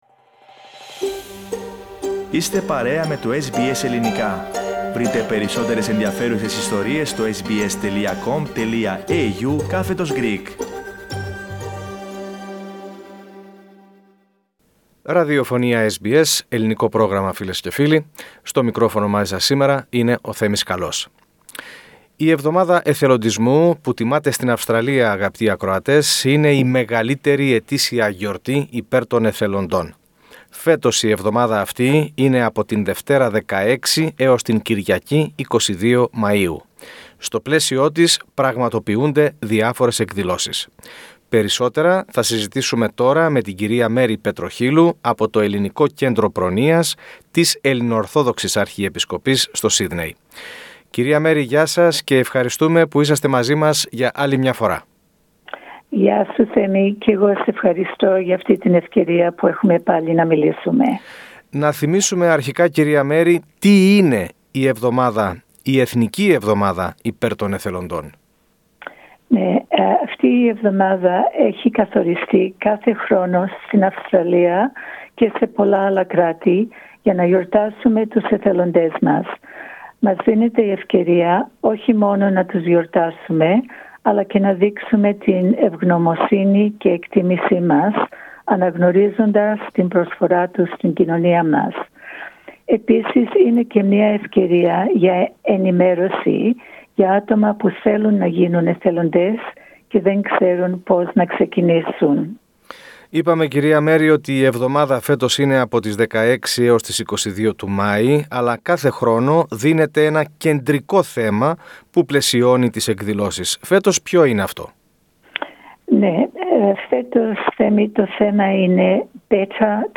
Μάθετε περισσότερα για τον εθελοντισμό στο Κέντρο Πρόνοιας της Αρχιεπισκοπής και πώς μπορείτε να συμμετάσχετε ακούγοντας το podcast της συνέντευξης.